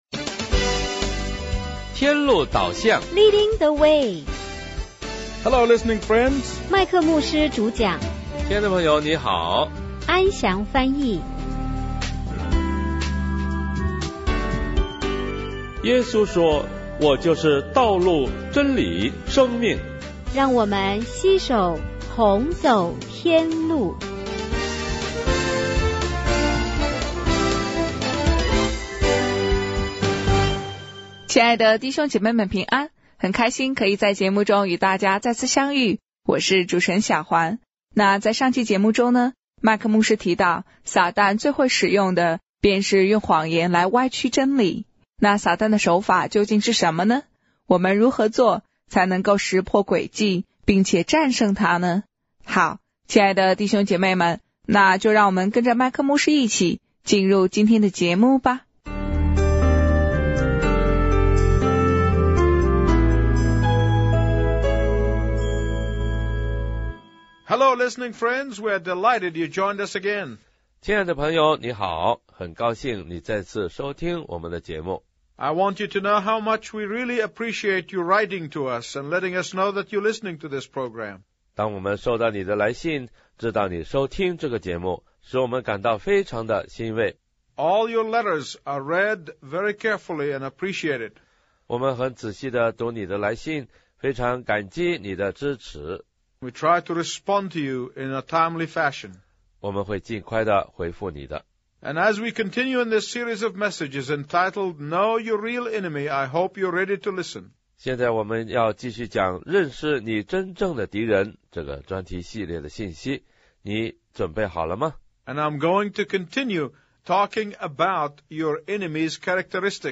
主题式讲座
借由讲座信息，配以诗歌、祷告、内容简介和扼要总结，让听众更能掌握和了解信息内容。逐句英译中的讲道，帮助听众更好地吸收信息，并提高英文水平。